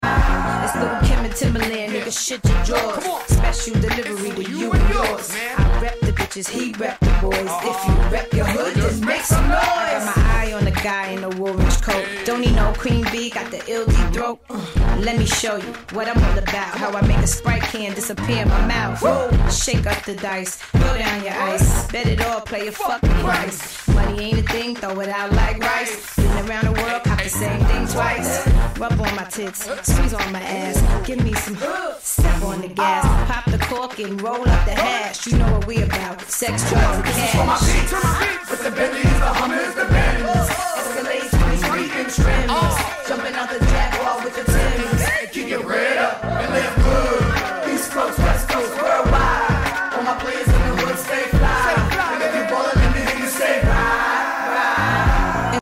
with swagger, confidence, and unforgettable energy
hip-hop